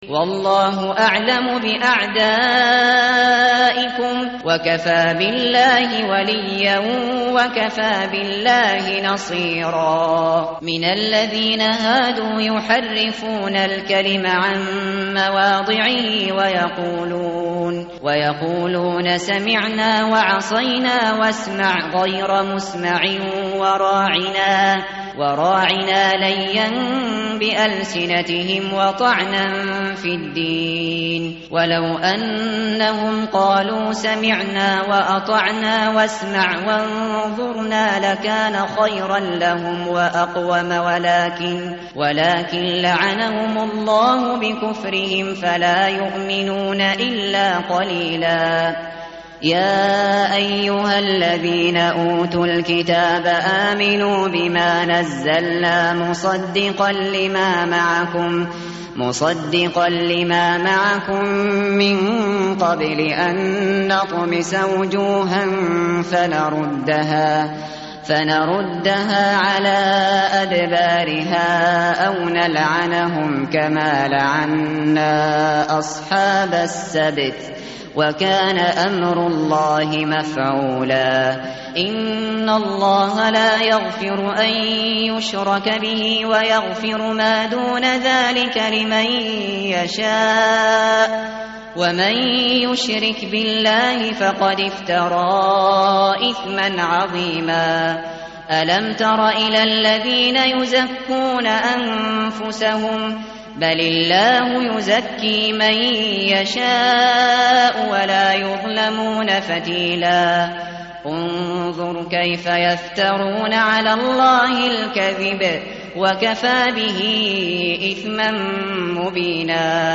متن قرآن همراه باتلاوت قرآن و ترجمه
tartil_shateri_page_086.mp3